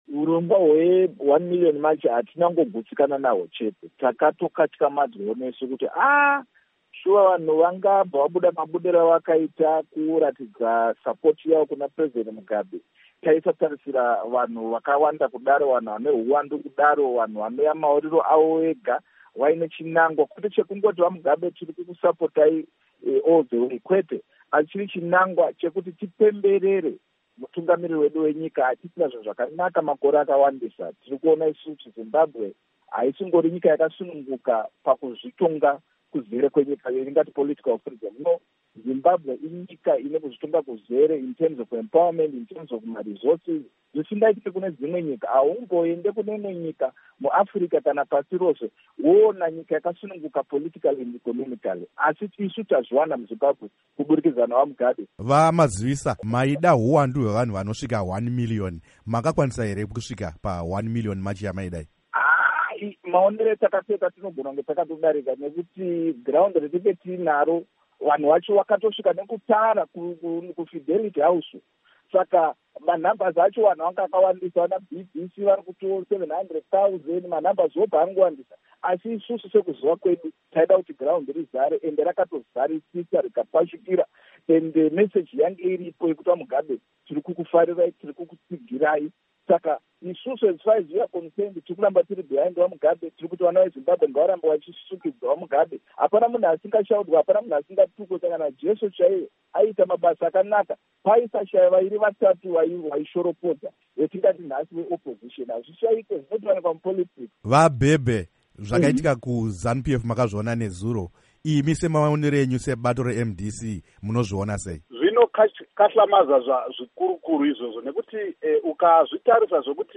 Hurukuro naVaPsychology Maziwisa